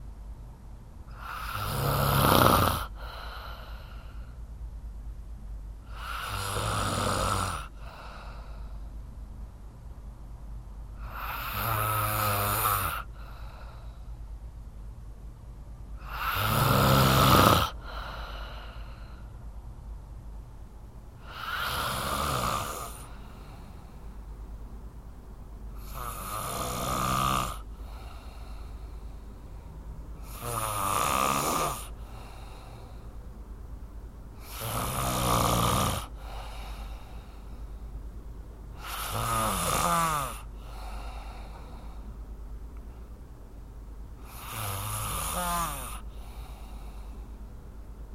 Звуки женского храпа
Звук сильного храпа женщины средних лет